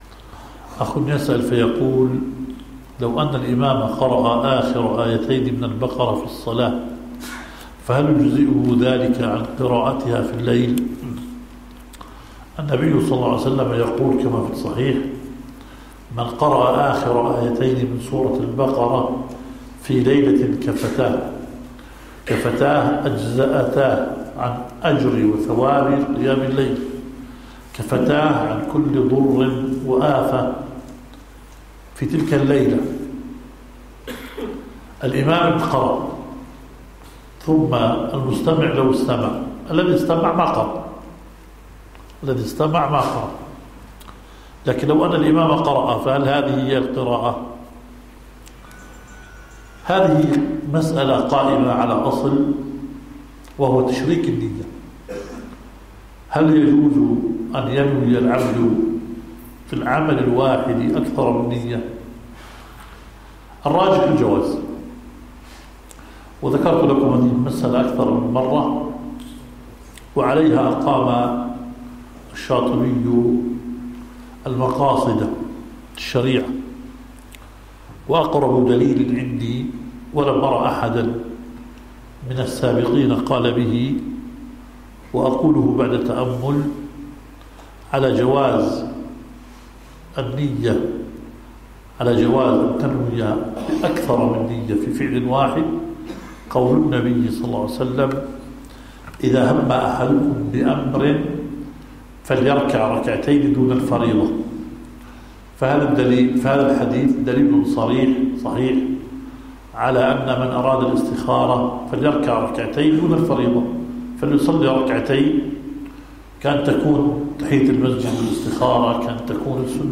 مجلس فتوى